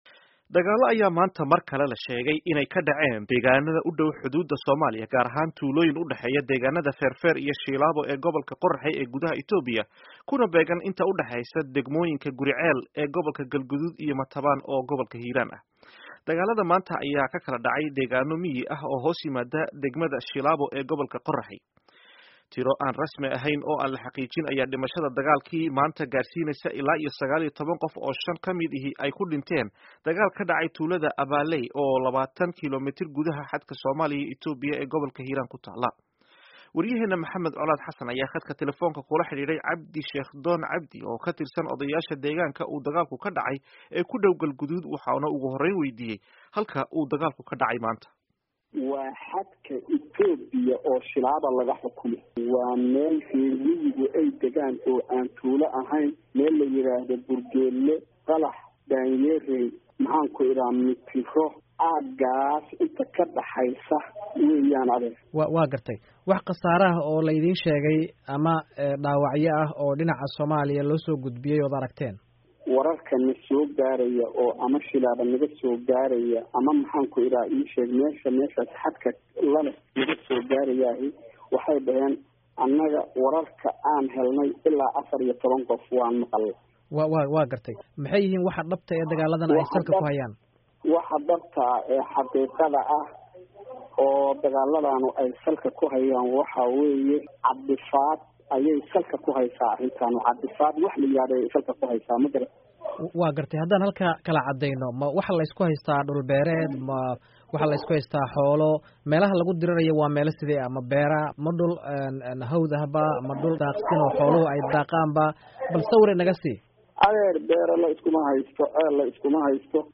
Waraysiga Dagaallada Galguduud